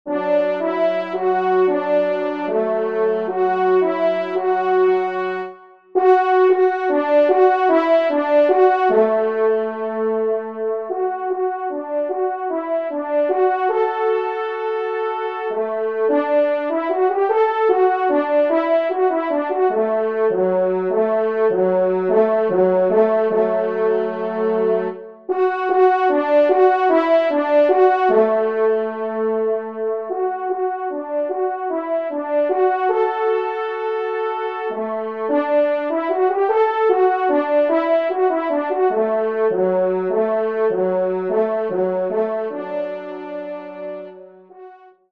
Genre :  Divertissement pour Trompes ou Cors en Ré et Picolo
ENSEMBLE